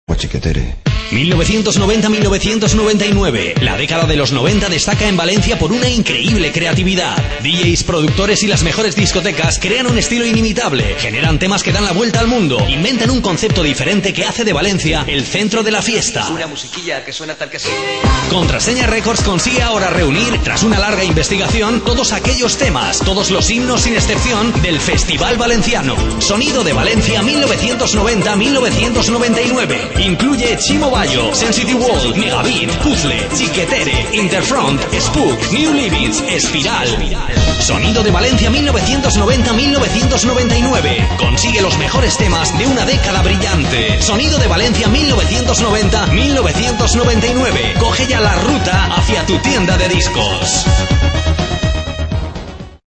Anuncio Radio